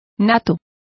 Complete with pronunciation of the translation of natural.